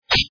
Il intervenait en visioconférence à partir de Goma.